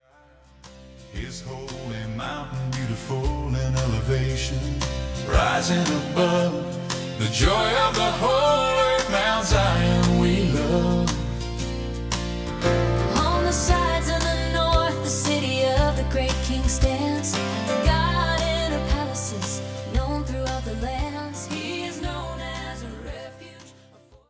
authentic Country worship
the authentic sound of modern Country worship
From intimate acoustic moments to full-band celebrations